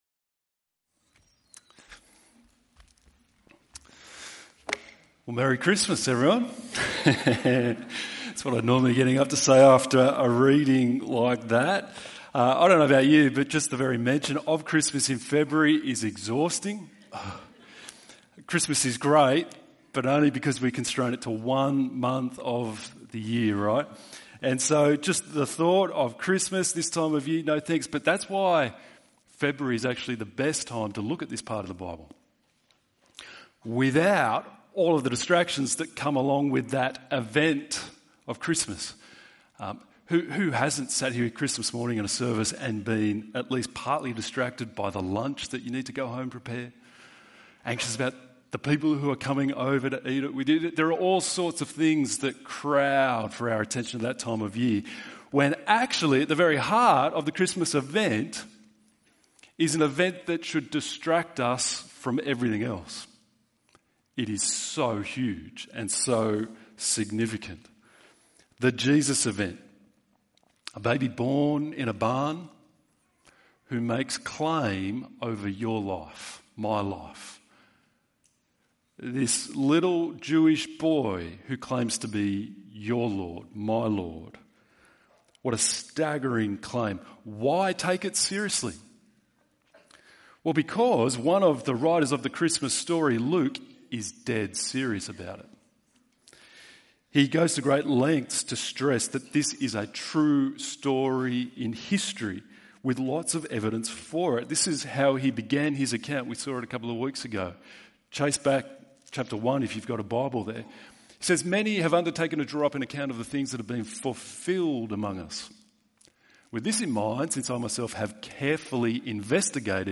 Arrival of Fulfilment - waiting and marvel ~ EV Church Sermons Podcast